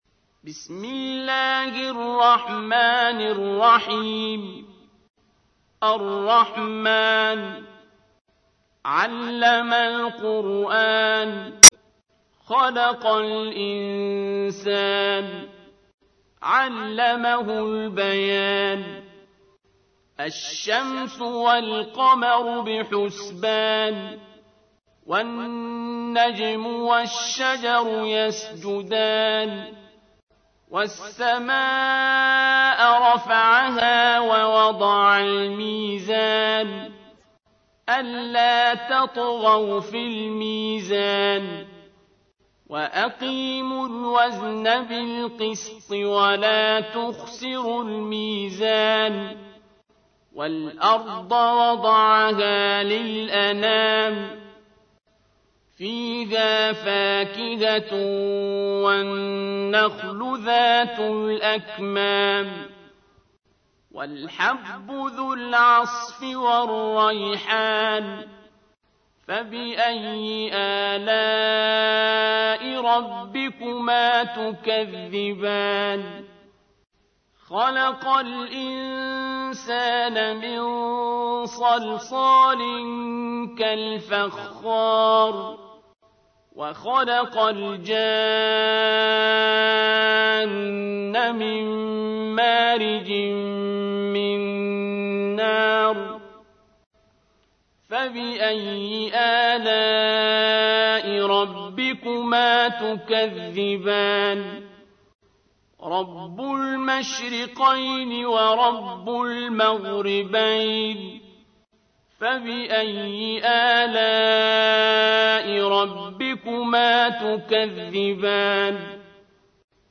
تحميل : 55. سورة الرحمن / القارئ عبد الباسط عبد الصمد / القرآن الكريم / موقع يا حسين